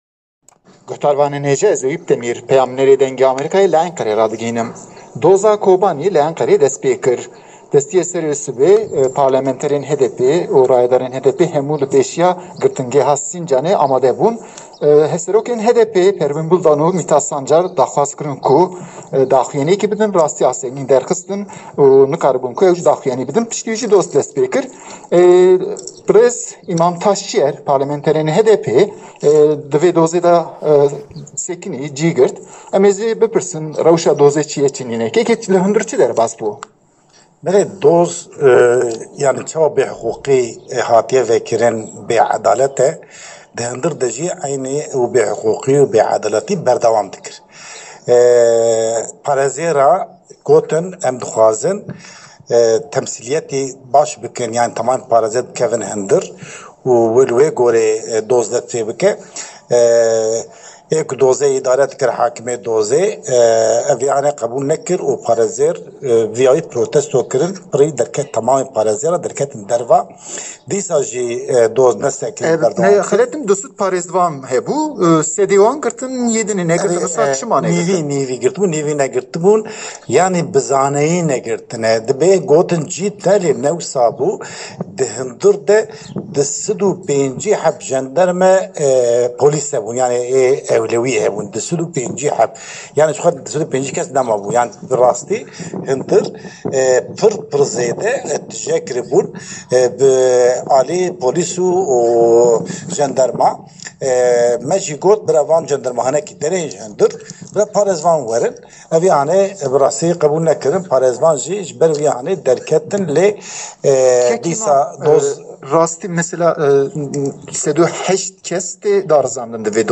Parêzerên Doza Kobanl li Enqerê li Otela Neva Palas bi preskonferansekê ev doz nîrxand